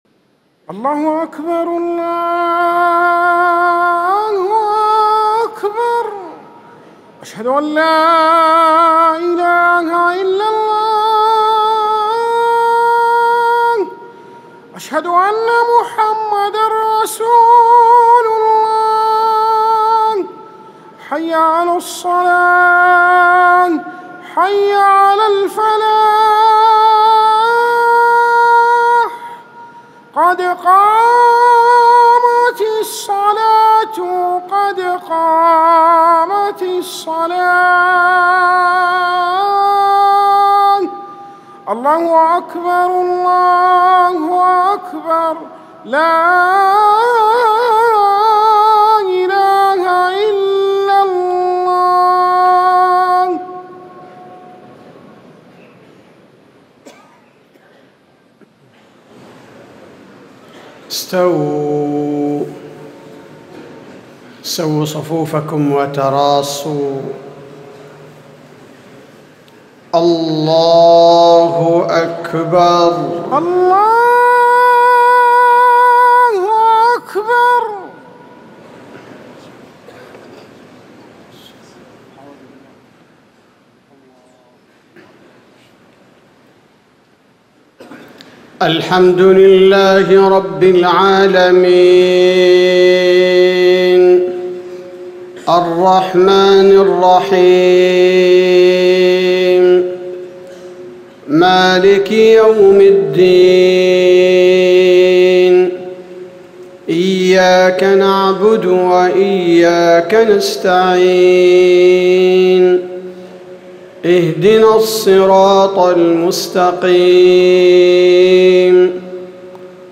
صلاة الفجر1-1-1440 سورة المعارج Fajr prayer from Surat Al-Ma'aarij > 1440 🕌 > الفروض - تلاوات الحرمين